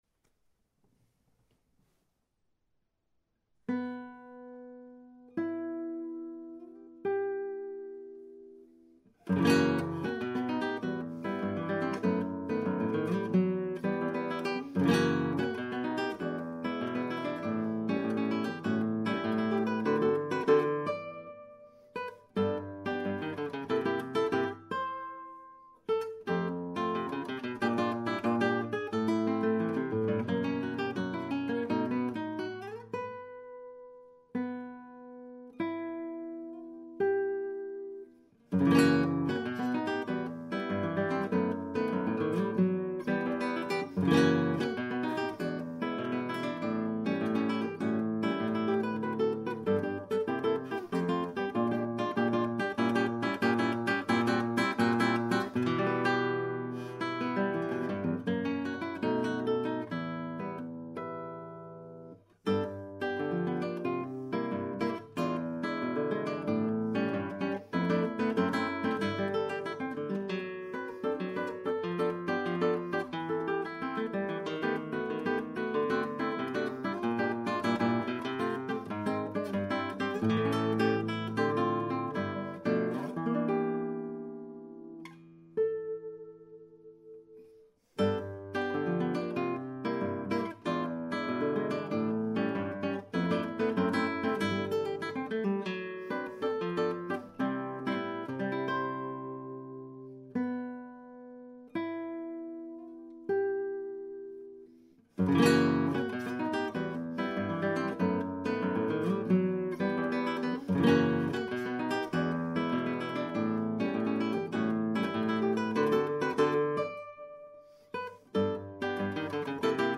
solista